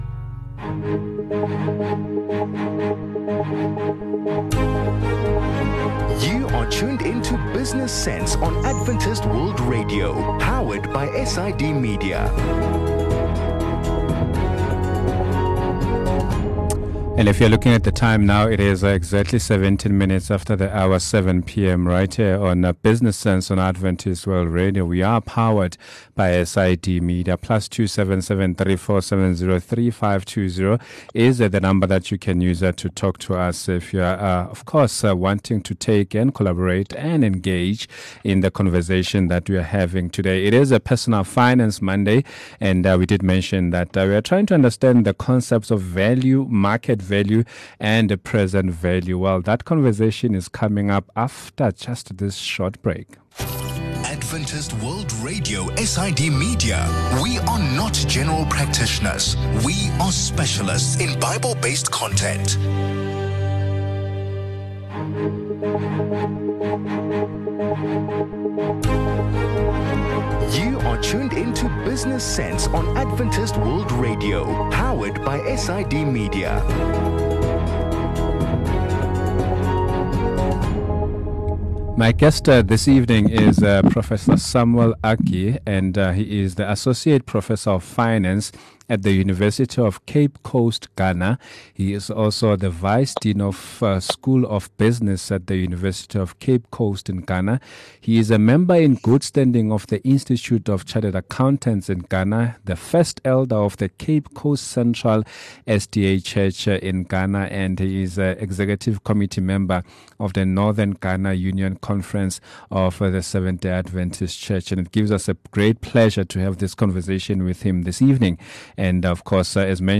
A lesson on the meaning of the three terms - Value, Market Value and Present Value.